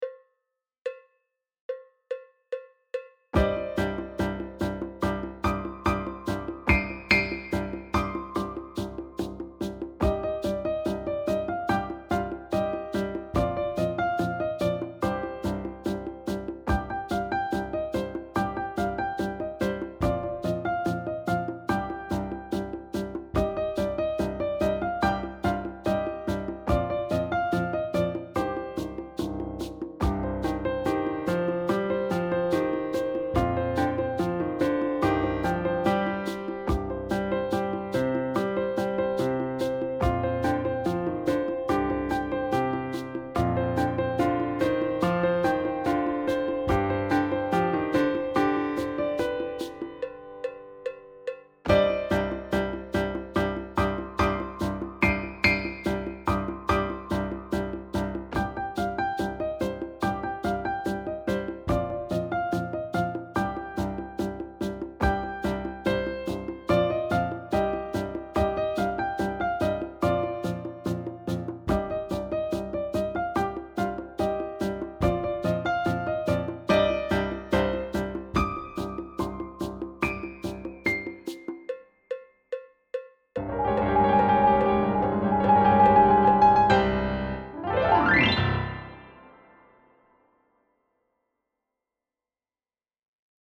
Key: C Minor
Time Signature: 4/4 (BPM ≈ 144)